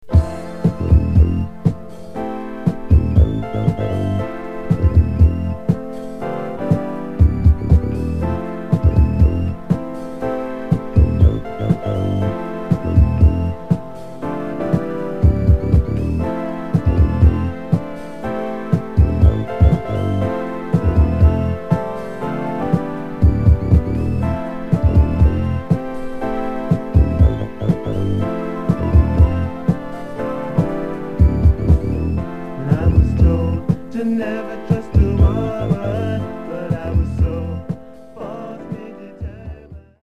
Genre: Soul/Funk